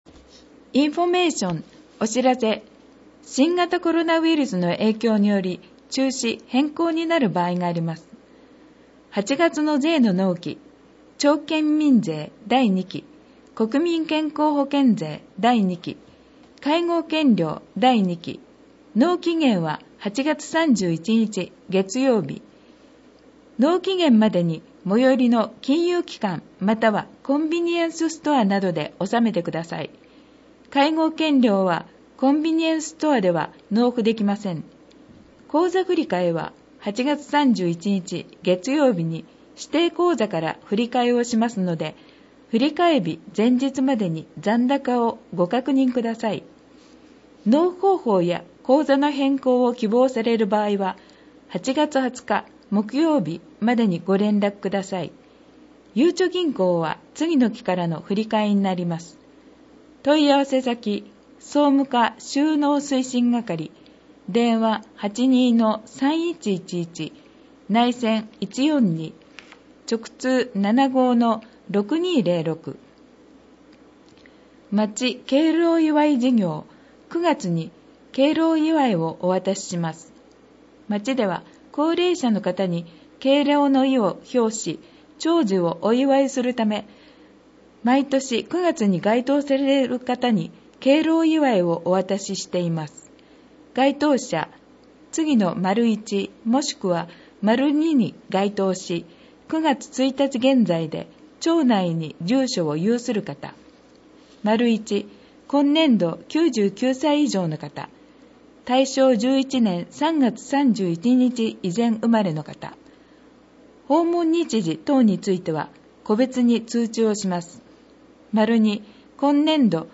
広報音訳版ダウンロード（制作：おとわの会）